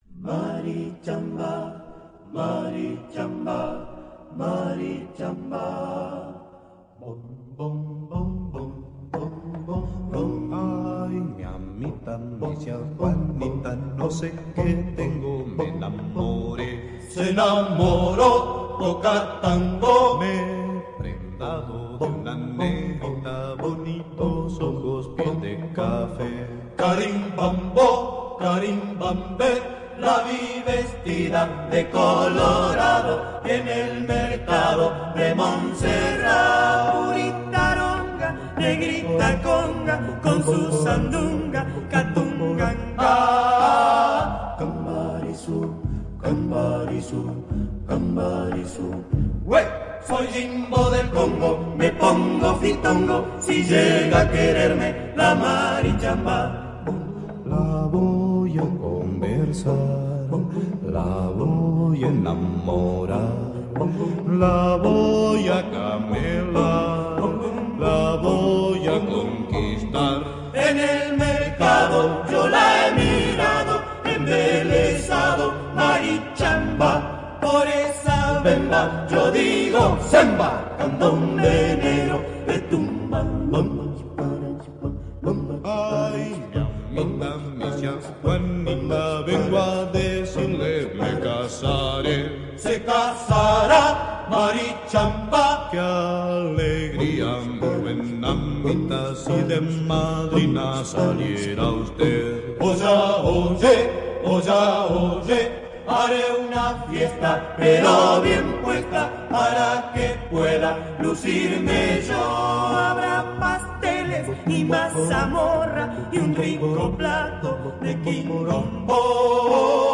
candombe